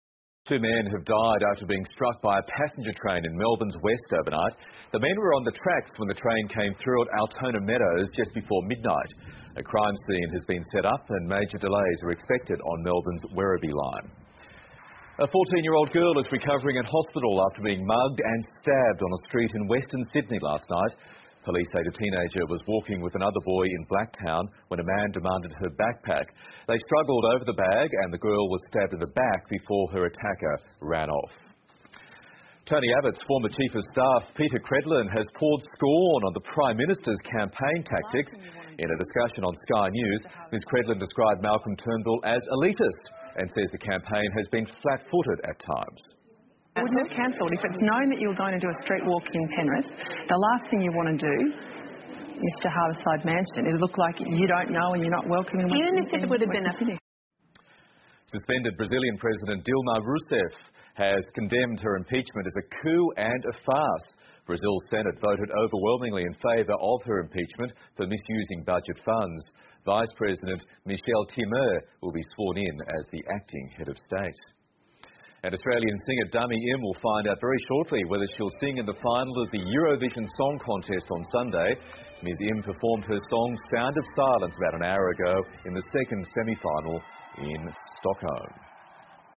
澳洲新闻 (ABC新闻快递) 2016-05-03 听力文件下载—在线英语听力室